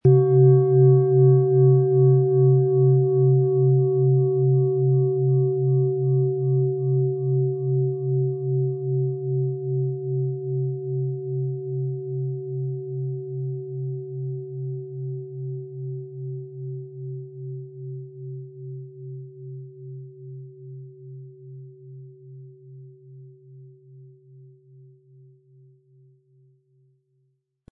Planetenton 1 Planetenton 2
• Tiefster Ton: Biorhythmus Geist
Um den Originalton der Schale anzuhören, gehen Sie bitte zu unserer Klangaufnahme unter dem Produktbild.
HerstellungIn Handarbeit getrieben
MaterialBronze